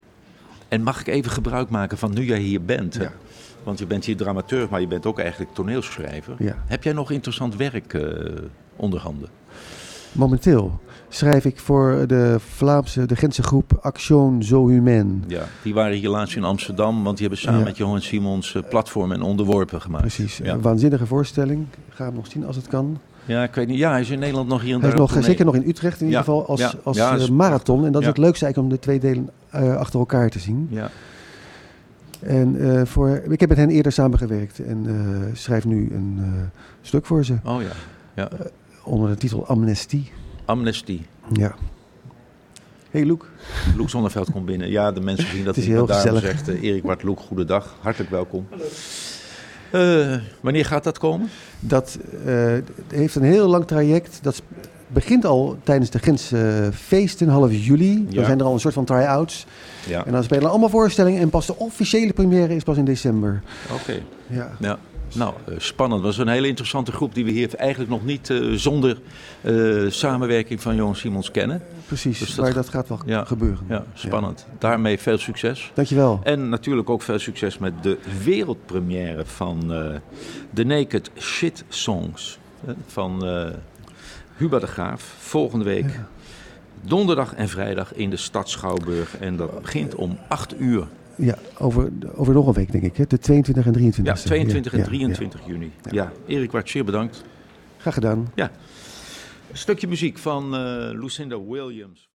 interview Theatermakers (radio)